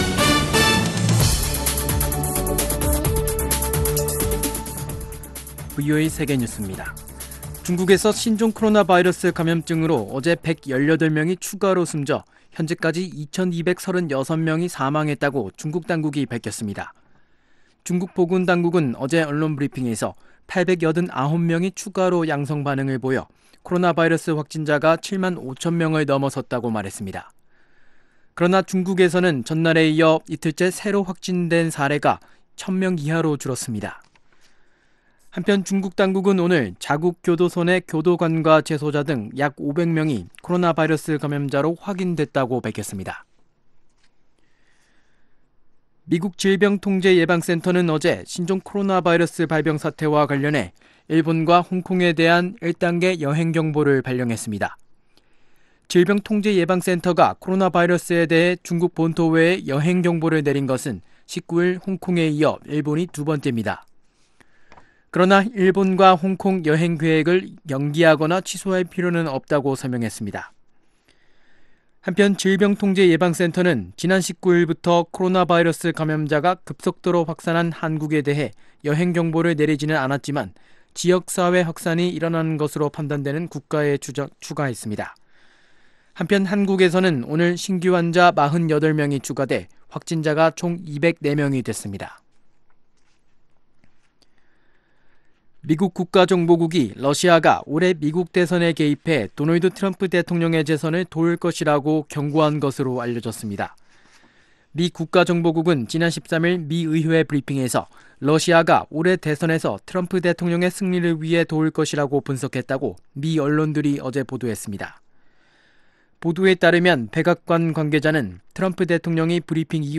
VOA 한국어 간판 뉴스 프로그램 '뉴스 투데이', 2020년 2월 21일 3부 방송입니다.